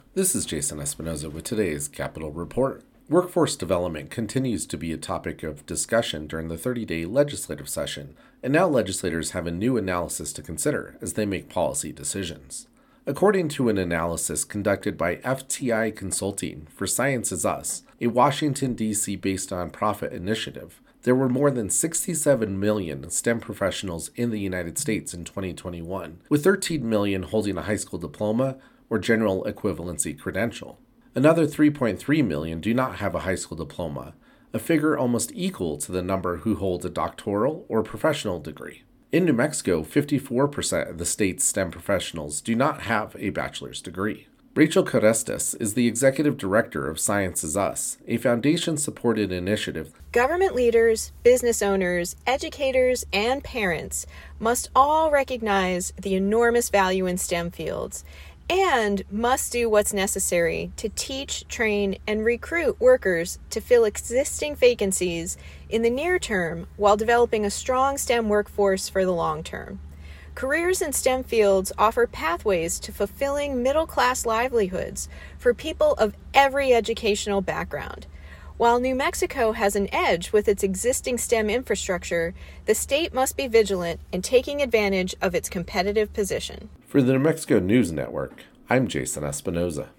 capitol reports